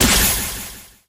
mech_bo_fire_01.ogg